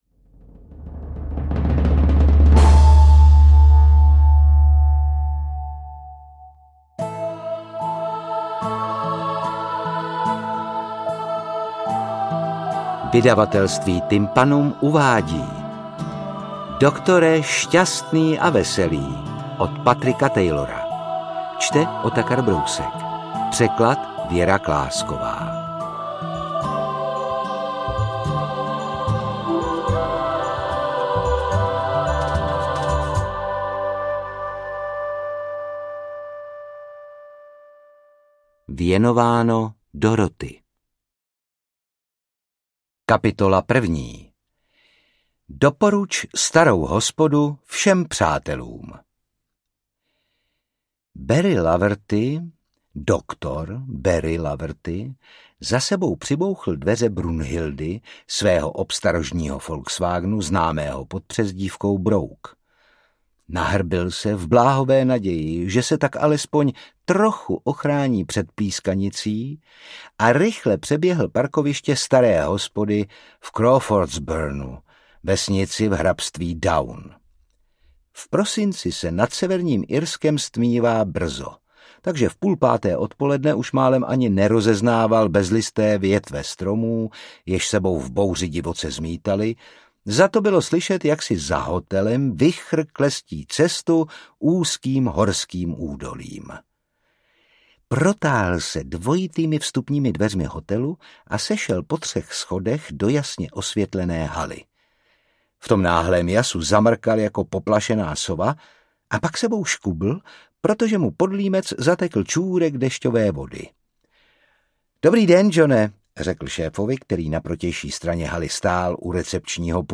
Interpret:  Otakar Brousek
AudioKniha ke stažení, 56 x mp3, délka 19 hod. 28 min., velikost 1071,3 MB, česky